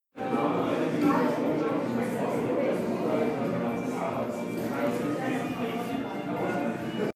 So ähnlich sich das wartende Publikum vor einem Vortrag anhört, so verschieden waren Publikum und auch Inhalte der beiden Veranstaltungen die ich in den letzten Wochen in Wien besucht habe.
vordemvortrag.mp3